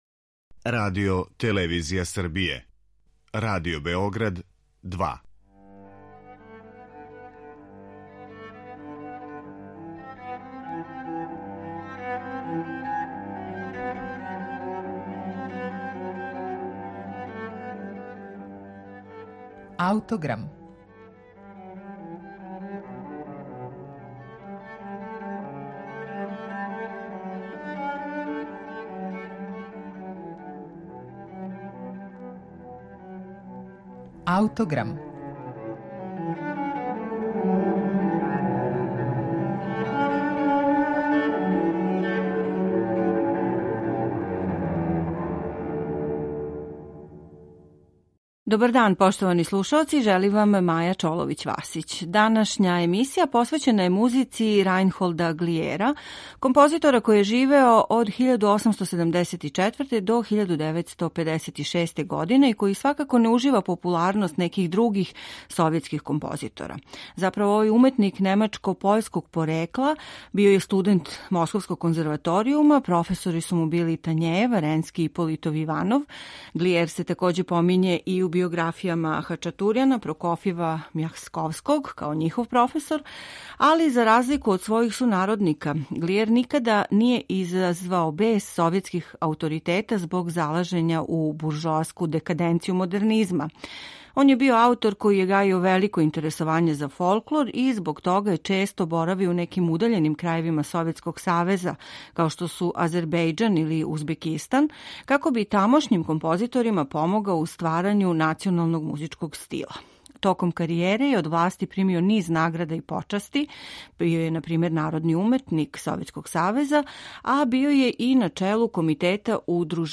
Kонцерт за хорну у Бе-дуру